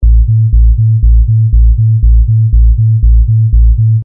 迪斯科低音合成器02
标签： 120 bpm Disco Loops Bass Loops 689.17 KB wav Key : Unknown
声道立体声